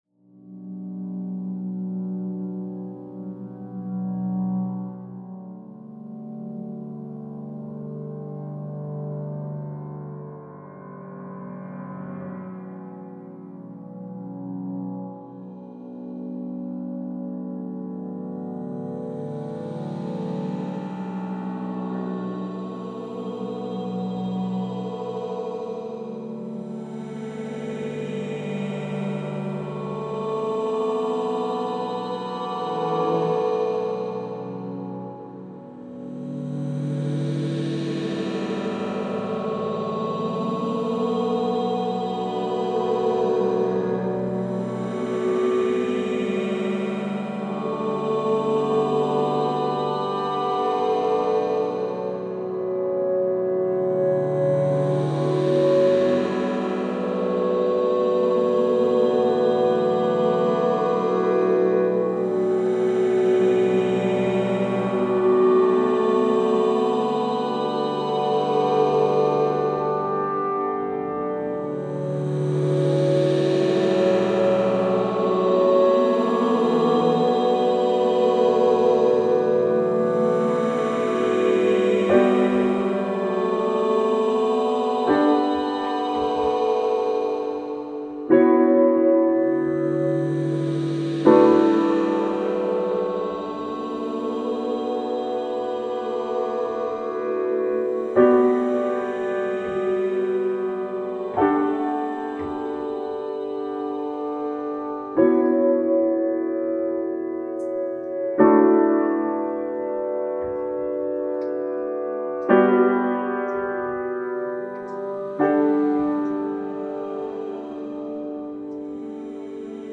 un pianiste et ermite américain
piano